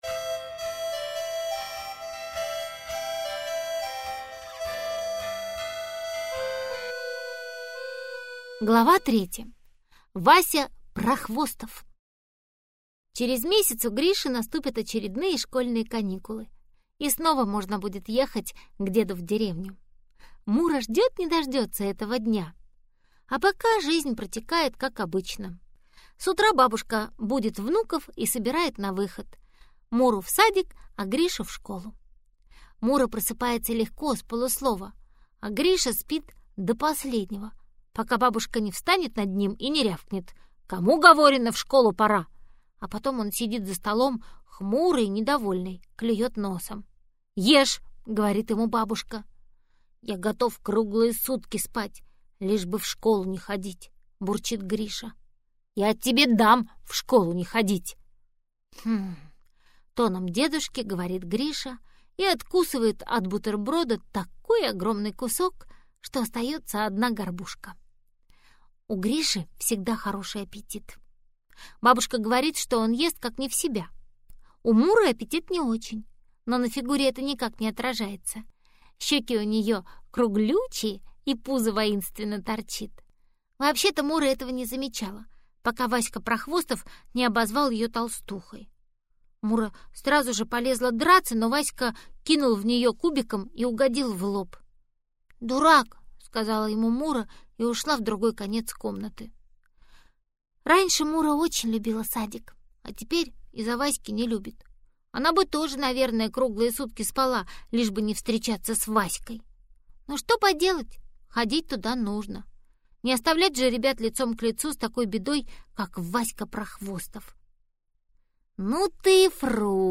Аудиокнига Счастье Муры | Библиотека аудиокниг